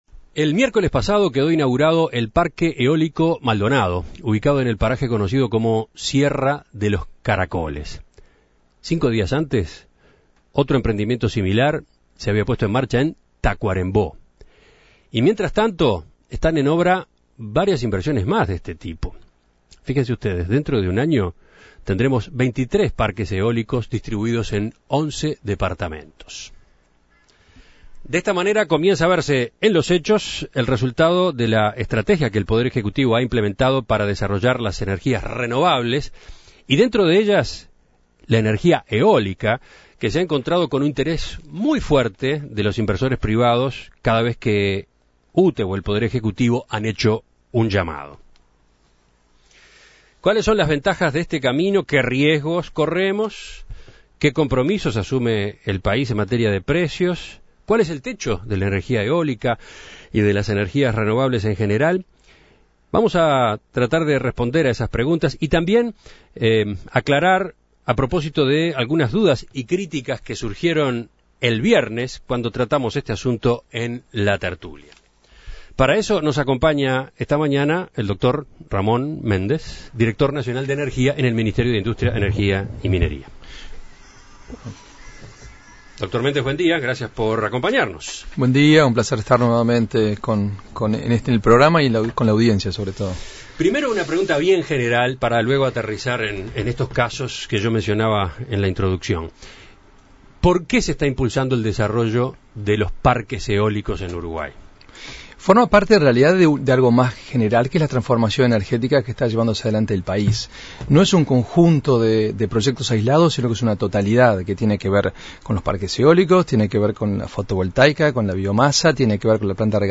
Para conocer más sobre estas instalaciones, En Perspectiva conversó con el director de Energía, Ramón Méndez. El jerarca destacó el hecho de que haya una política energética a largo plazo por primera vez en la historia del país.